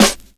Medicated Snare 37.wav